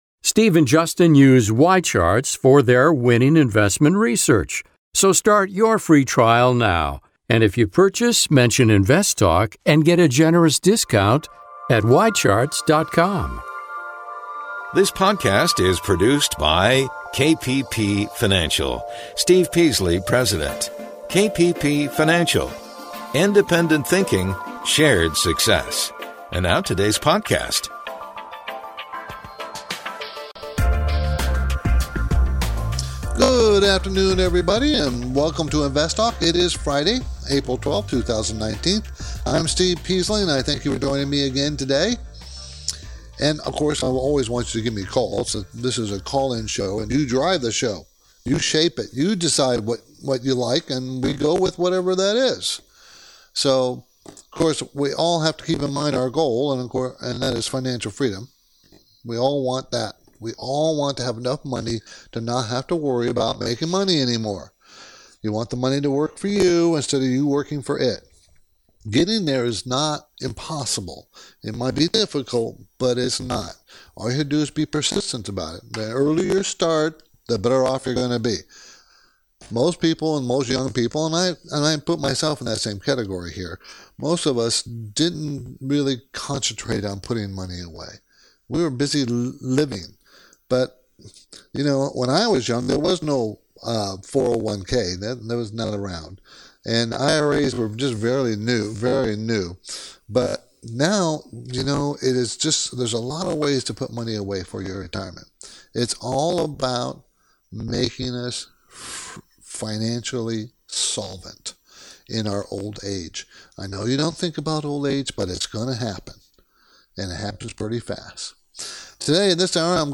Podcast Listeners: We are experiencing an intermittent technical issue that may cause the audio stream to break-up or sound distorted.